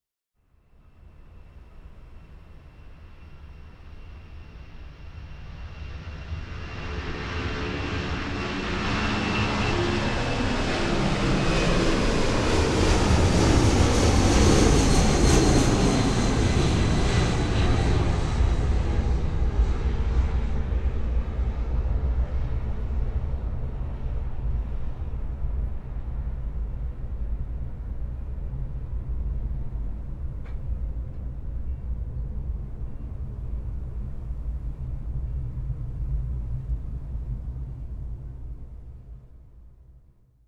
Boeing 747 пролетел прямо над головой